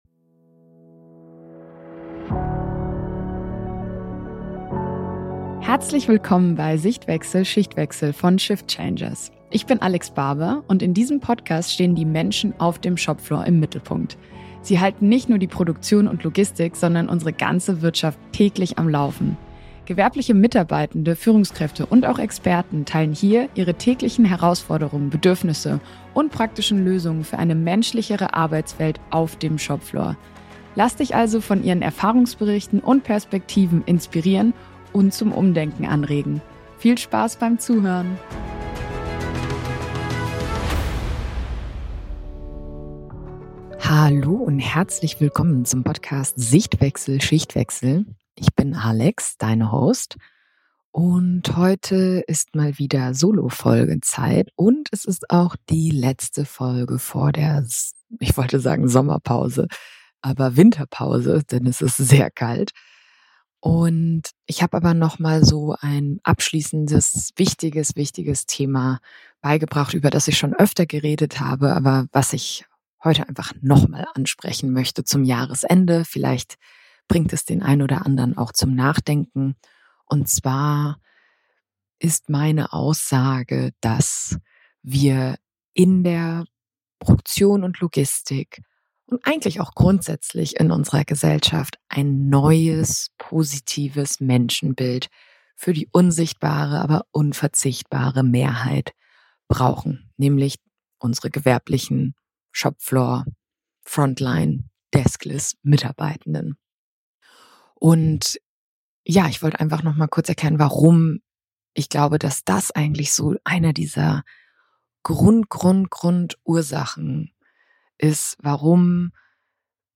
In der letzten Solo-Folge des Jahres geht es um ein Thema, das mir besonders am Herzen liegt und das im Zentrum fast aller Shopfloor-Herausforderungen steht: unser Bild von gewerblichen Mitarbeitenden.